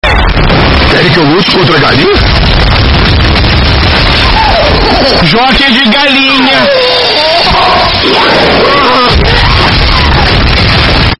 Categoria: Sons de memes
Áudio completo, estourado, no melhor estilo meme brasileiro.